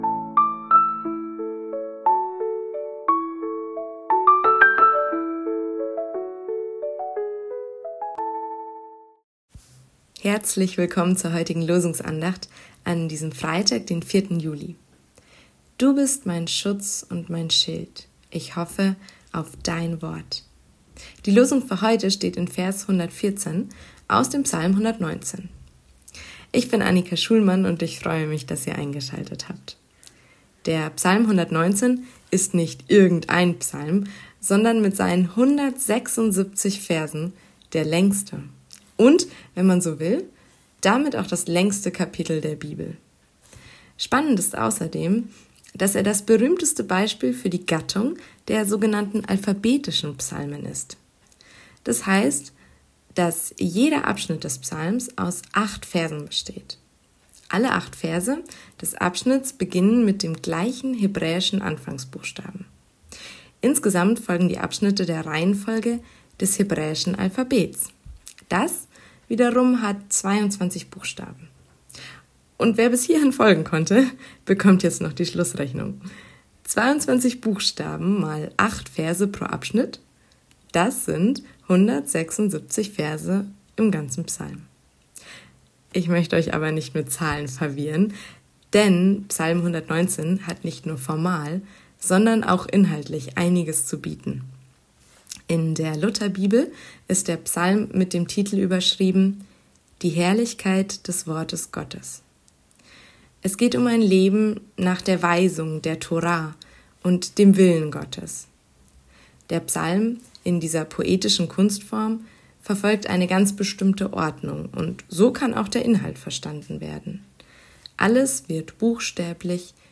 Losungsandacht für Freitag, 04.07.2025